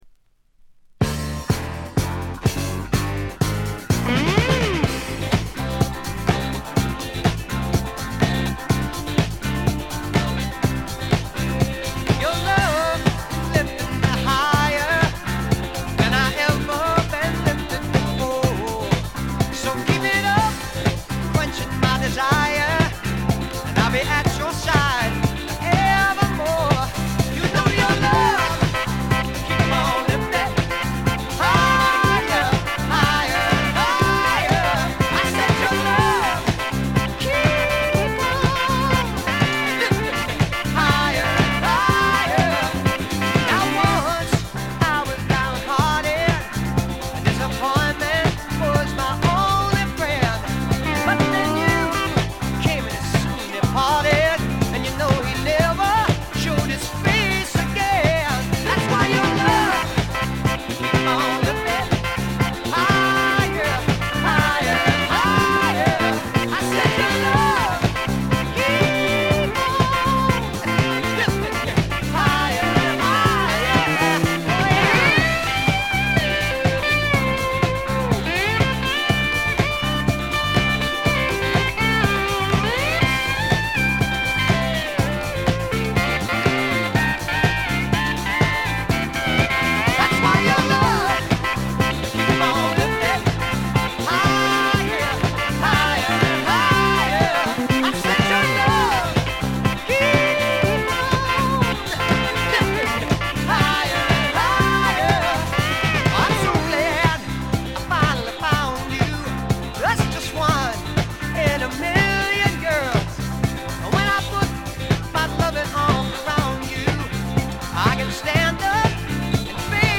ほとんどノイズ感無し。
美しいコーラスが特に気持ち良いです。
試聴曲は現品からの取り込み音源です。
Recorded At - Kaye-Smith Studios